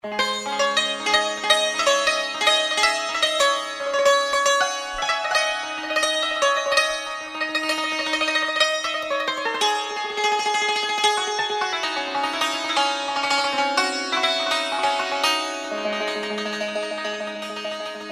زنگ خور موبایل ملایم(سنتی بی کلام)